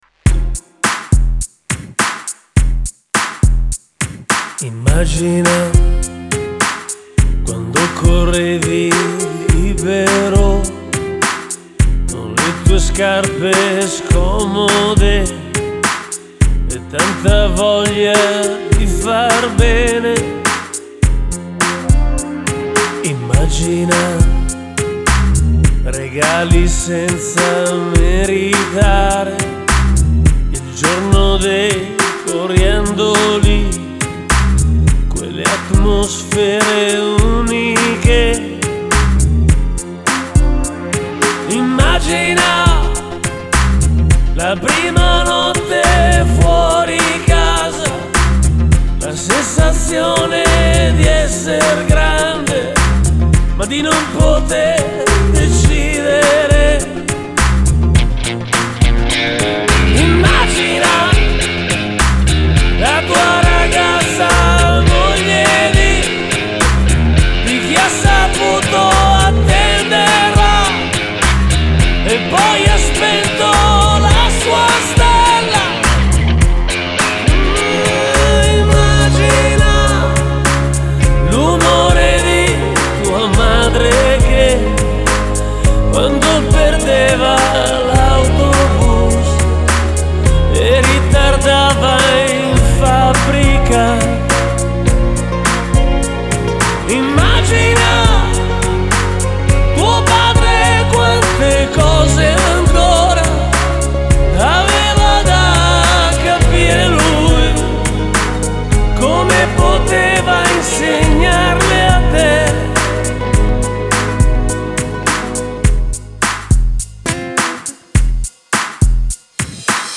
Genre: Pop / Italia